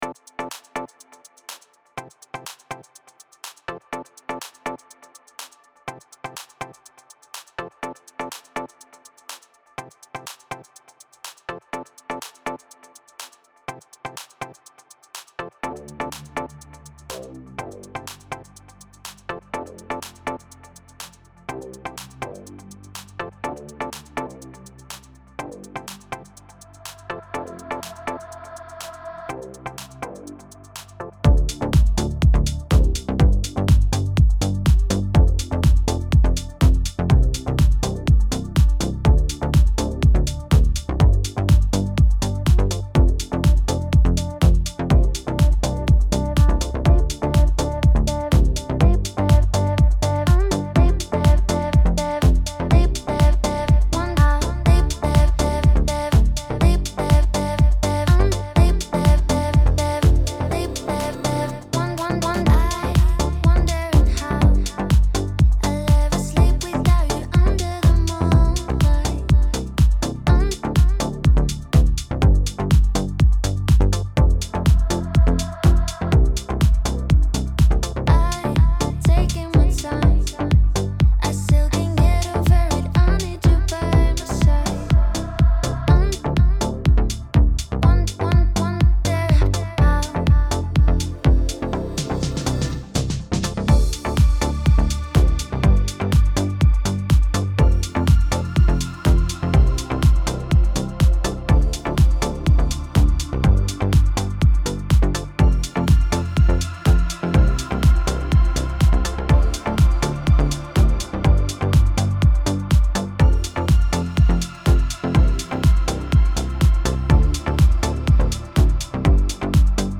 Piano Solo,
Das ist gerade nur das Grund Arrangement. So stelle ich mir das vor, da hab ich ein langen Break.